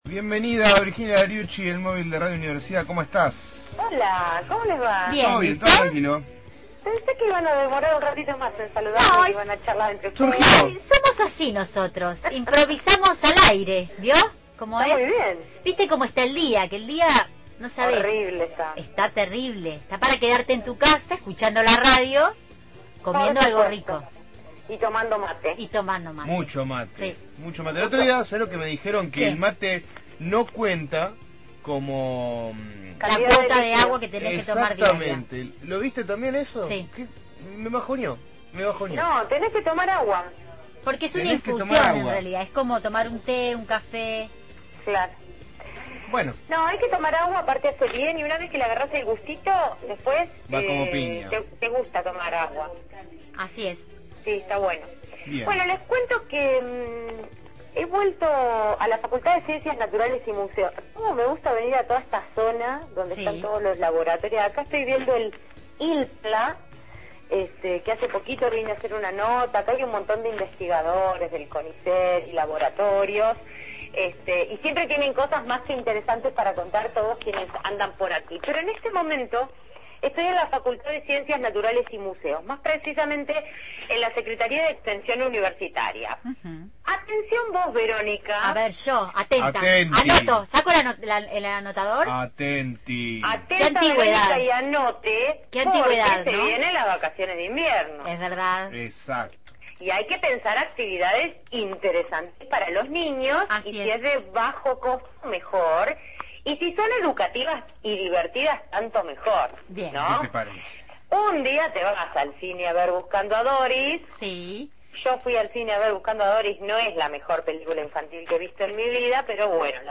Móvil/ Propuestas para la vacaciones de la Facultad de Naturales – Radio Universidad